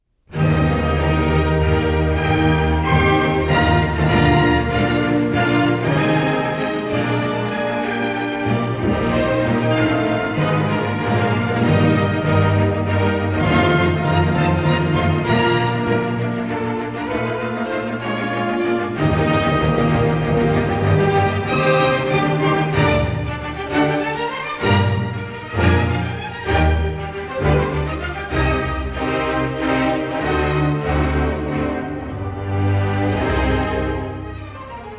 in E flat major
Lebhaft